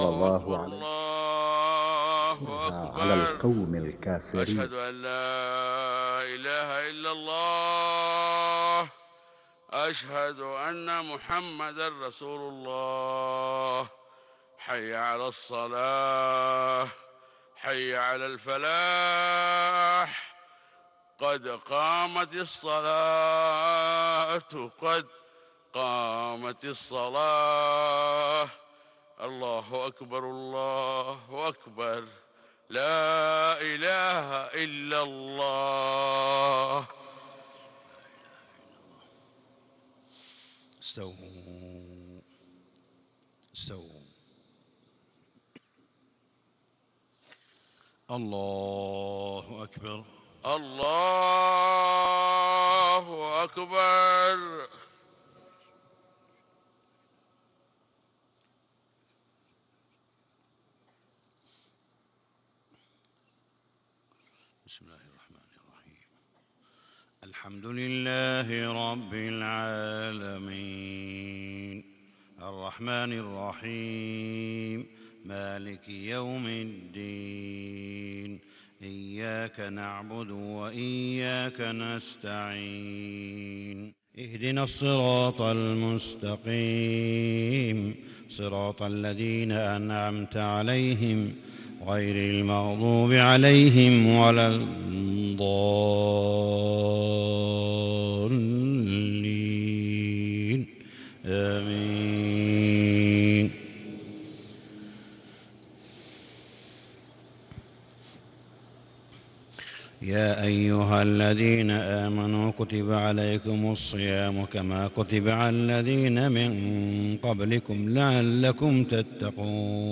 صلاة الفجر 1رمضان 1437هـ من سورة البقرة 183-188 > 1437 🕋 > الفروض - تلاوات الحرمين